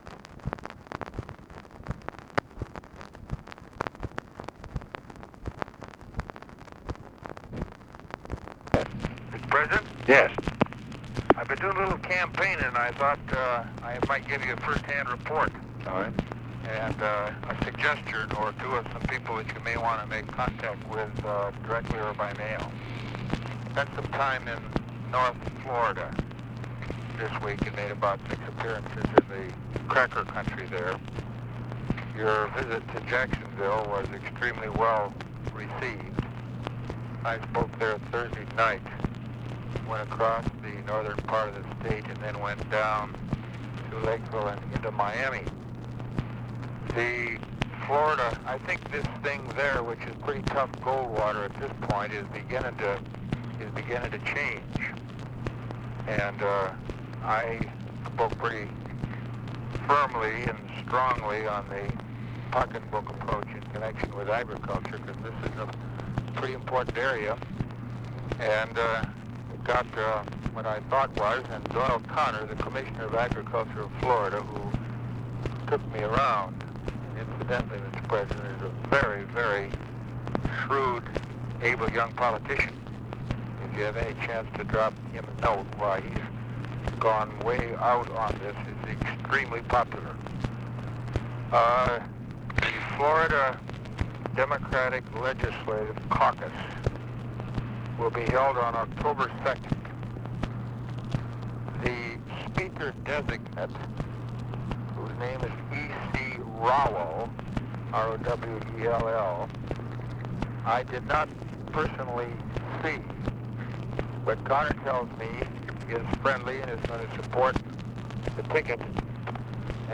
Conversation with ORVILLE FREEMAN, September 27, 1964
Secret White House Tapes